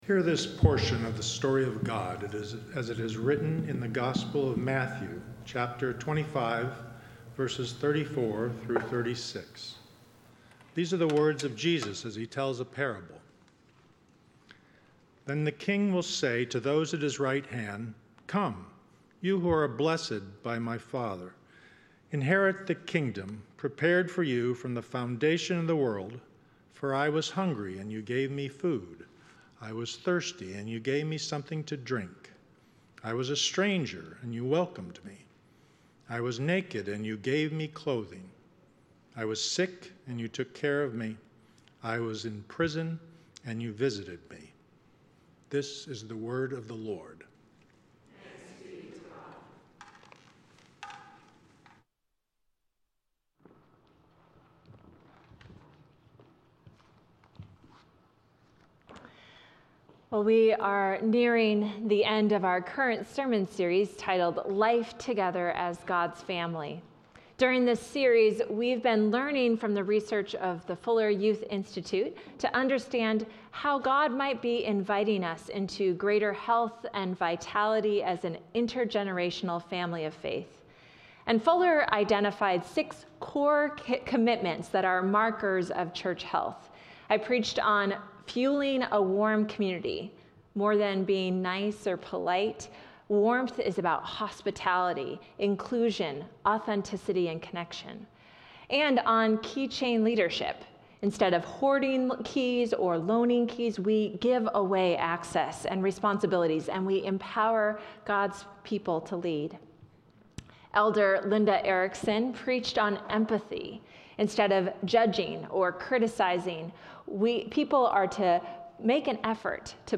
Service Type: Sunday Morning « Pentecost—Something Old